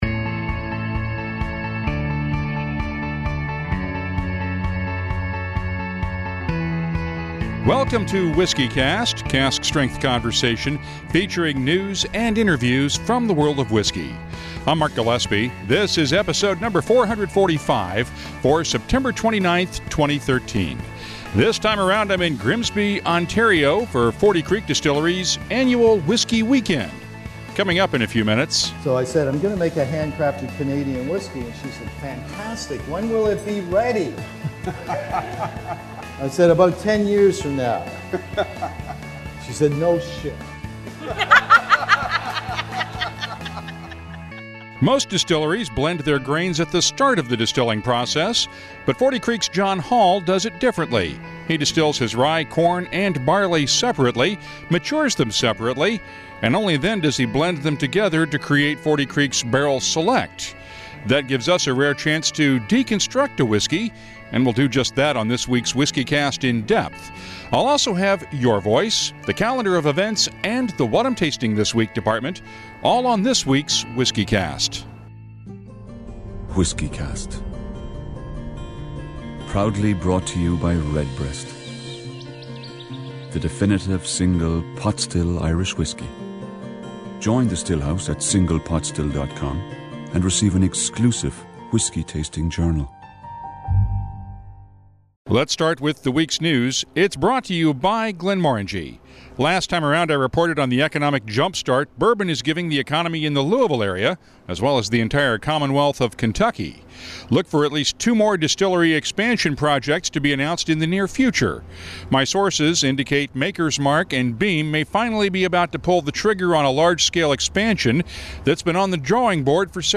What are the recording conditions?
This week's WhiskyCast comes from Forty Creek Distillery's annual Whisky Weekend in Grimsby, Ontario.